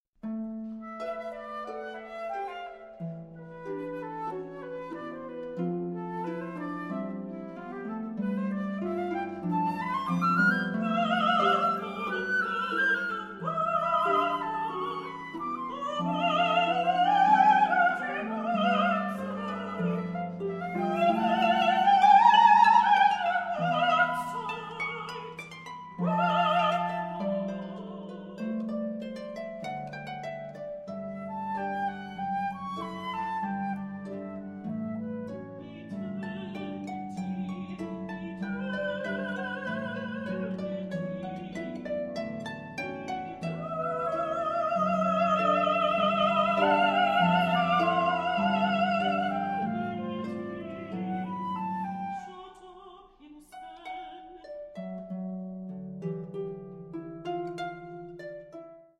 InstrumentationSoprano, Flute, and Harp